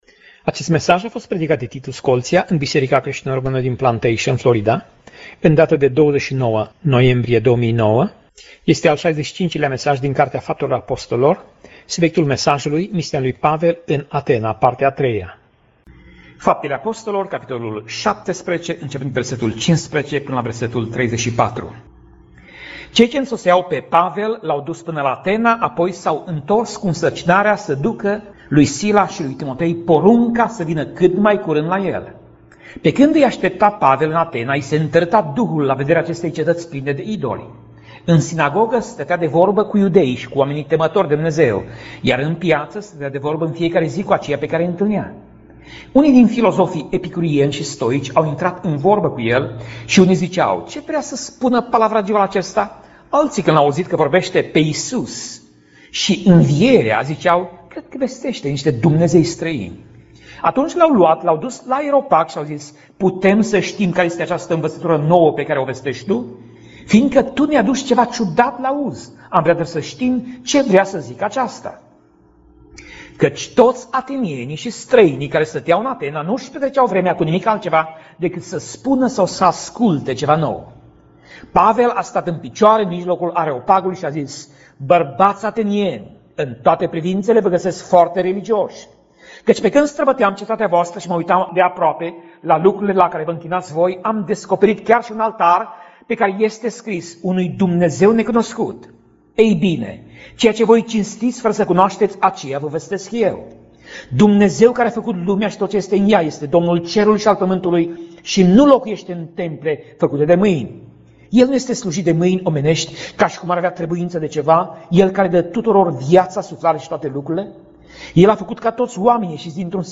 Pasaj Biblie: Faptele Apostolilor 17:15 - Faptele Apostolilor 17:34 Tip Mesaj: Predica